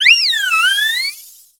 Cri de Nymphali dans Pokémon X et Y.